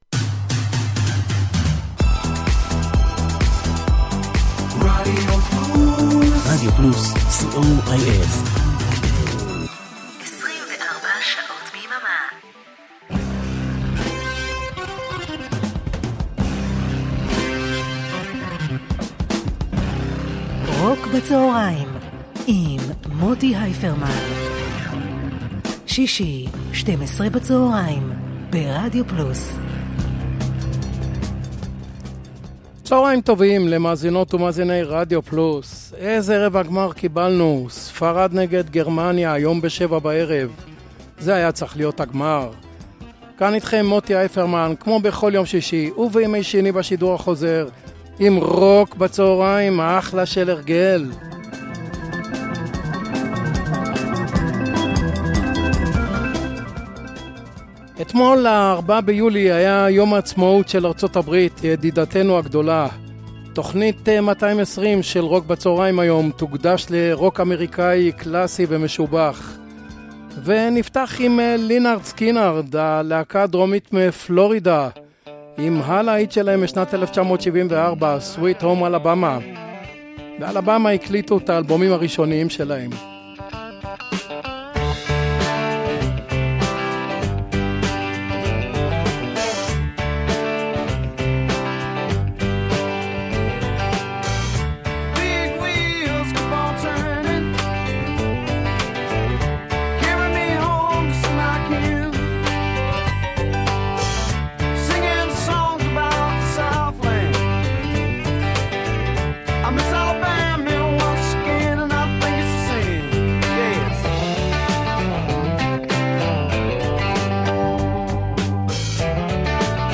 blues rock
pop rock